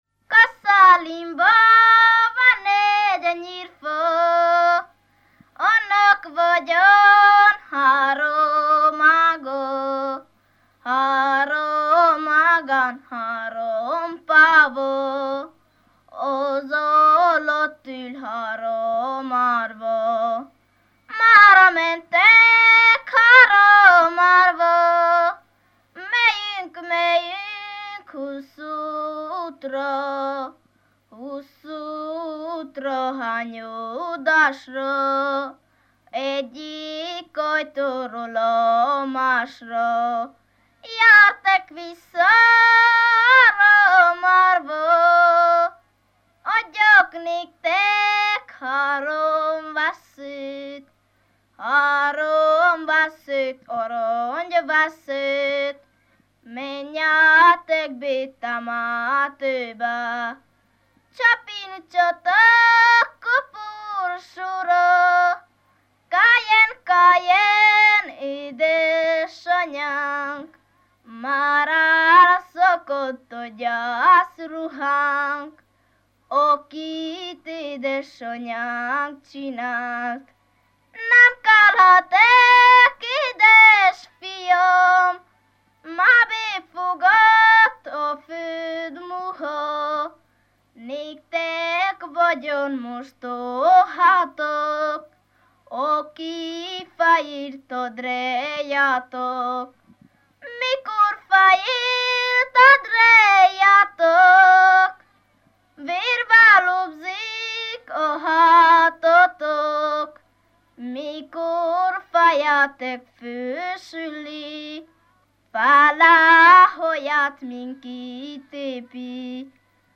ének
ballada
Lészped
Moldva (Moldva és Bukovina)